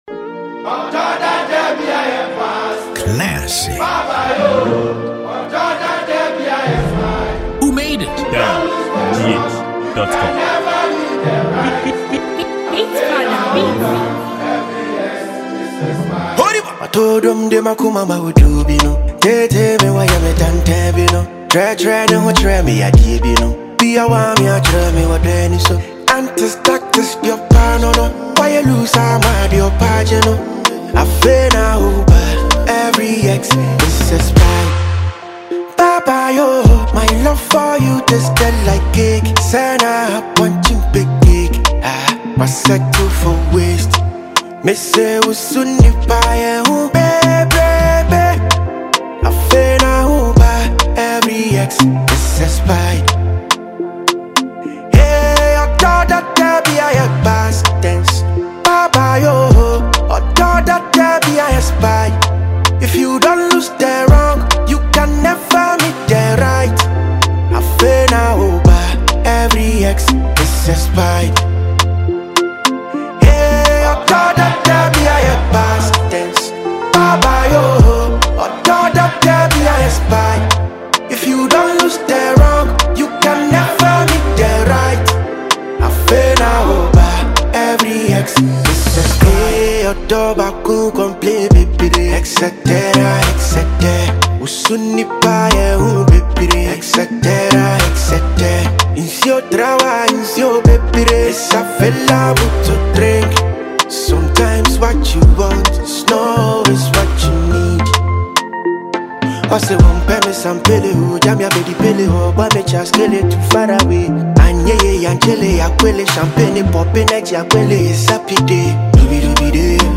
a song released by Ghanaian highlife musician and songwriter